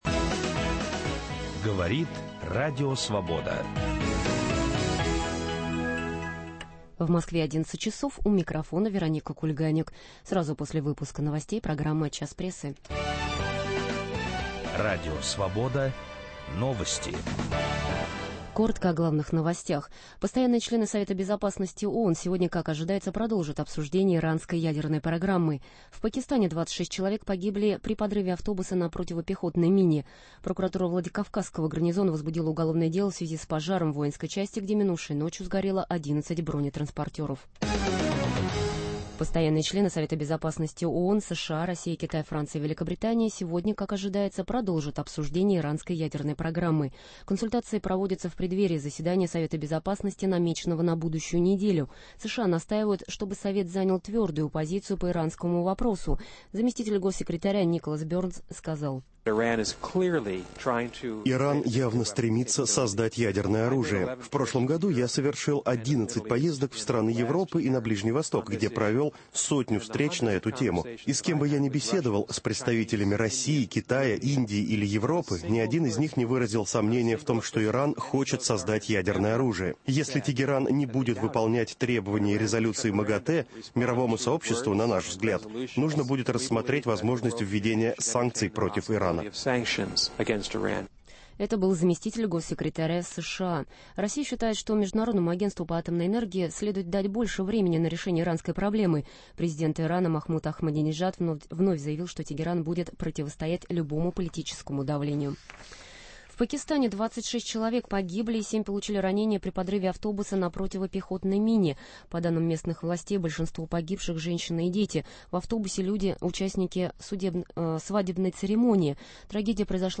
Об этом в беседе